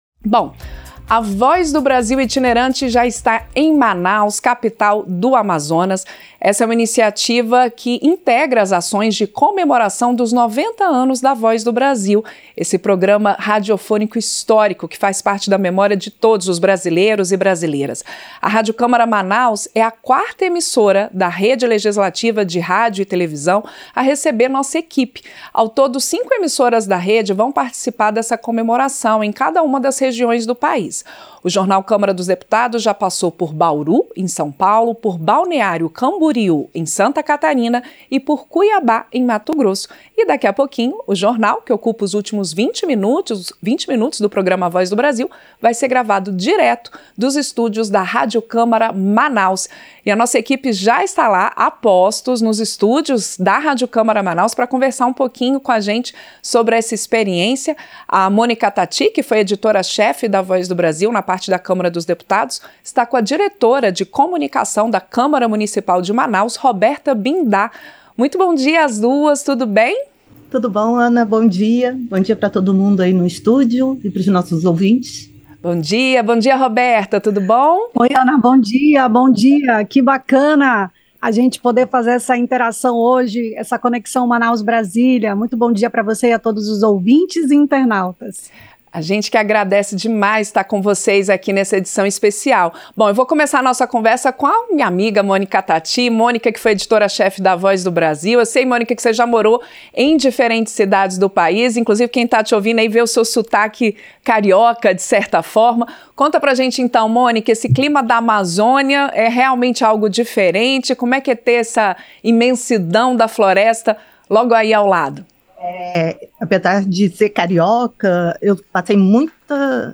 Gravação itinerante de “A Voz do Brasil” chega a Manaus (AM); ação integra comemorações dos 90 anos do programa - Rádio Câmara
Como parte das comemorações dos 90 anos do programa “A Voz do Brasil”, o Jornal da Câmara dos Deputados, que ocupa os últimos 20 minutos do histórico noticiário brasileiro, vai ser gravado nos estúdios de cinco emissoras da Rede Legislativa de Rádio e TV. O programa vai percorrer todas as regiões do país para apresentar o noticiário mais antigo do rádio brasileiro em conjunto com emissoras que fazem parte desta rede.
Entrevistas